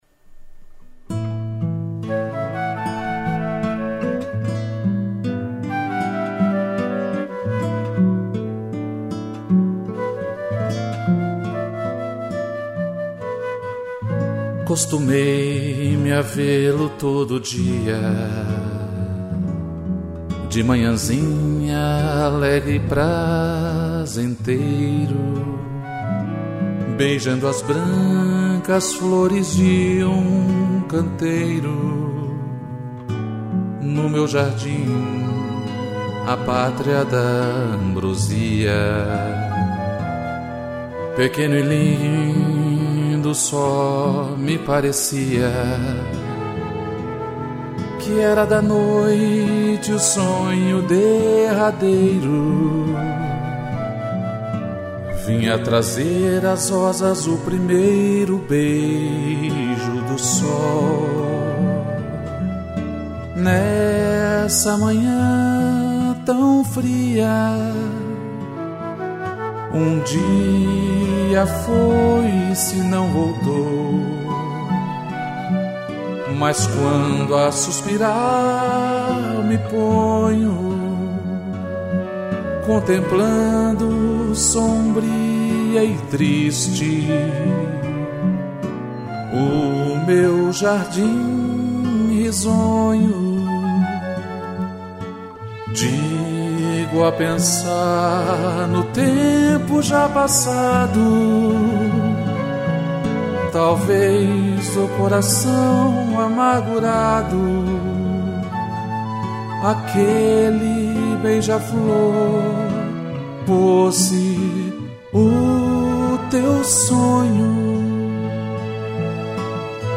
voz e violão
violino e cello